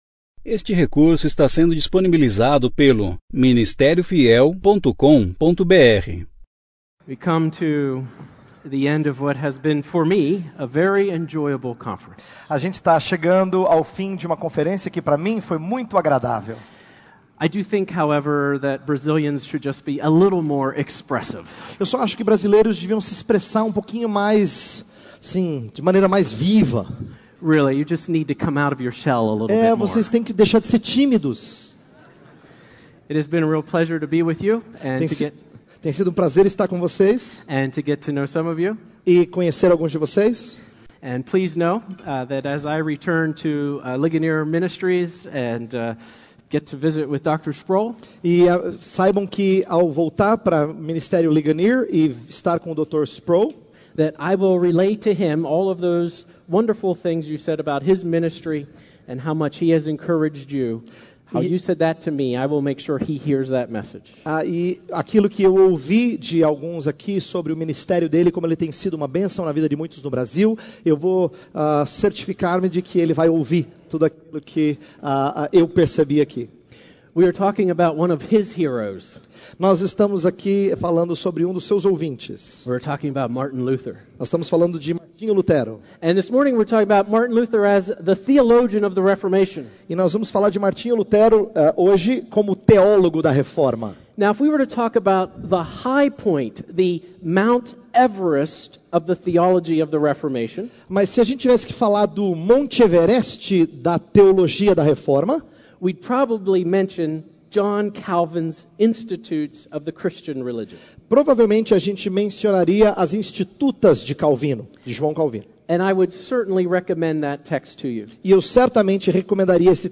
Conferência: 33ª Conferência Fiel para Pastores e Líderes – Brasil Tema